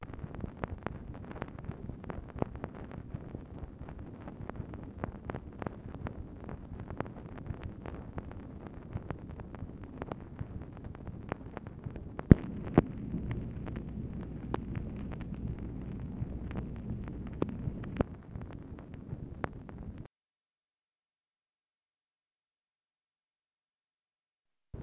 MACHINE NOISE, November 23, 1964
Secret White House Tapes | Lyndon B. Johnson Presidency